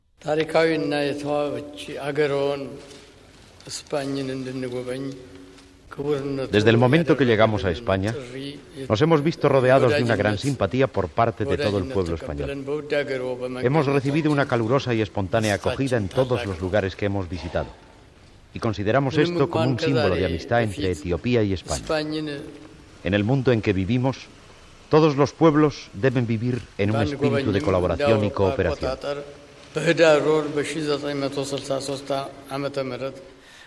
Paraules de l'emperador d'Etiòpia Haile Selassie en la seva visita a l'Estat espanyol
Informatiu
Fragment extret del programa "Audios para recordar" de Radio 5 emès el 14 de maig del 2018.